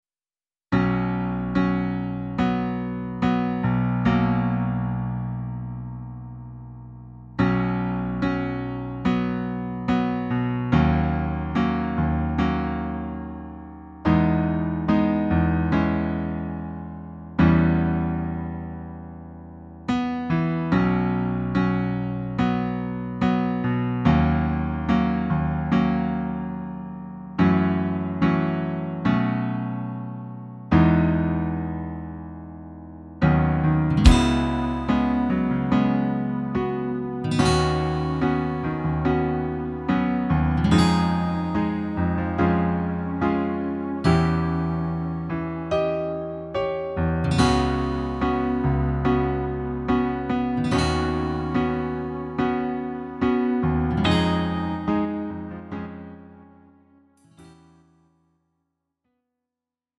음정 원키 4:05
장르 가요 구분 Lite MR